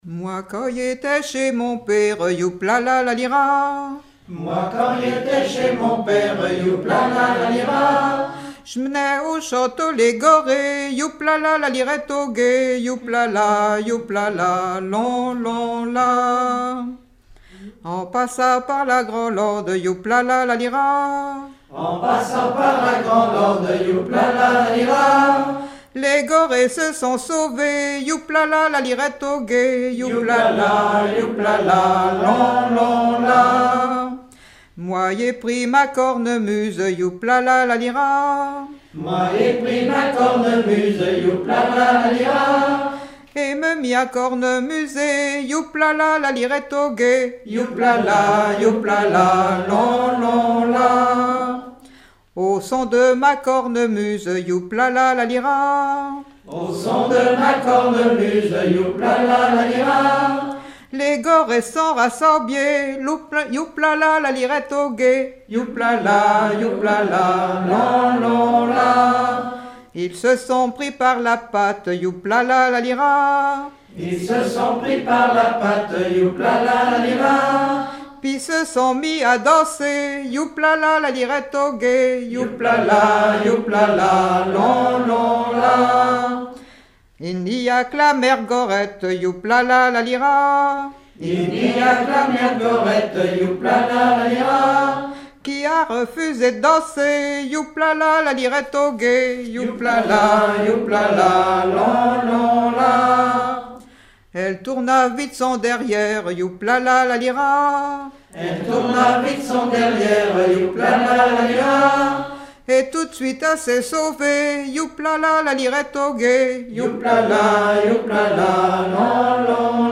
Genre laisse
collectif de chanteurs du canton
Pièce musicale inédite